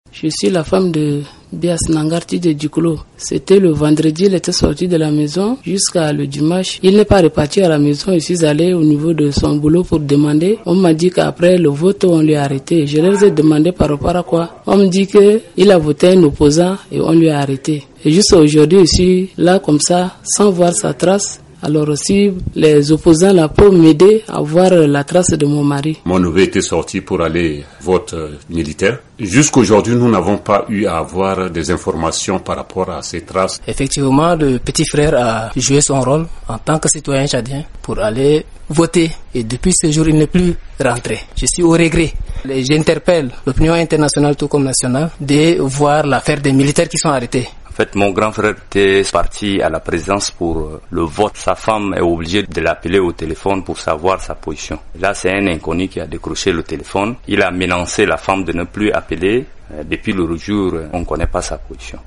Témoignages de quelques parents de militaires tchadiens arrêtés